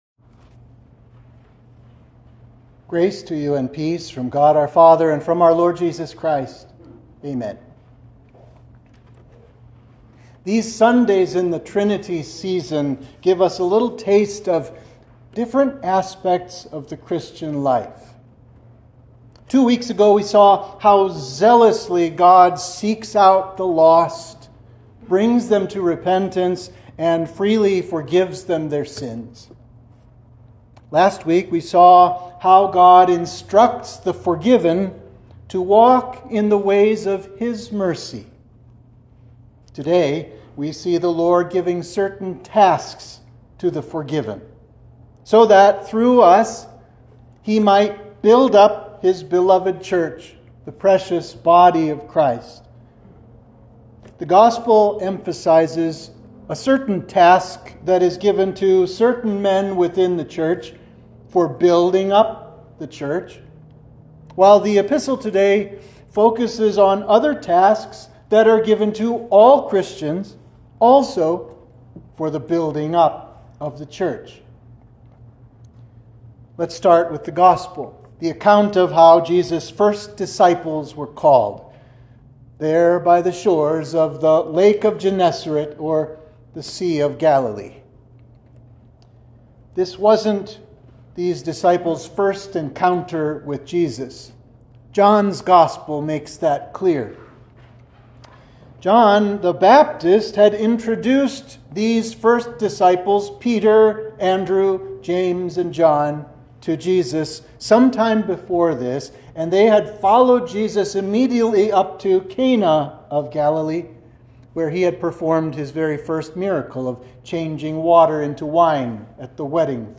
Sermon for Trinity 5
(Only the audio of the sermon is available for today’s service.)